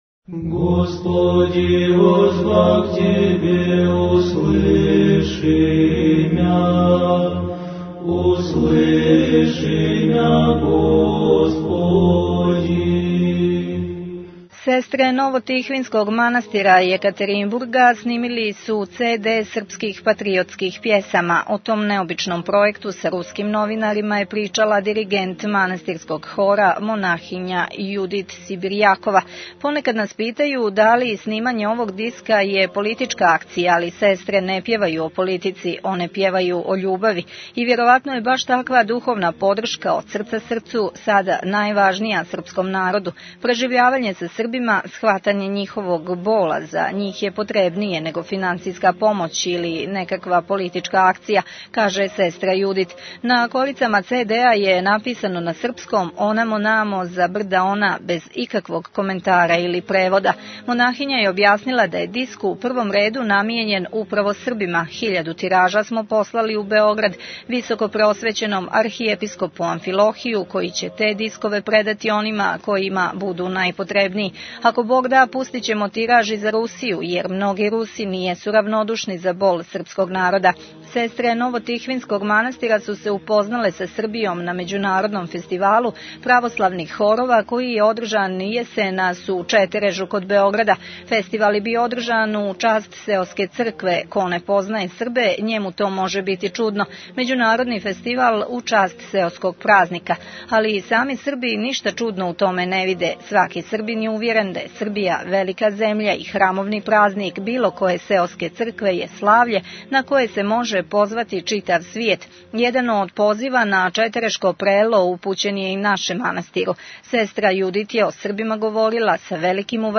Руске монахиње снимиле цд српских патриотских пјесама Tagged: Записи 49:10 минута (8.45 МБ) Сестре Ново-Тихвинског манастира из Јекатеринбурга снимиле су цд српских патриотских пјесама . Монахиње манастира посвећеног Мајци Божјој Тихвинској биле су гости Митрополије Црногорско - приморске у јулу 2005. године када су и гостовале на Радио - Светигори и говориле о овој Уралској Светињи, животу у њој и чудотворењима Мајке Божије Тихвинске.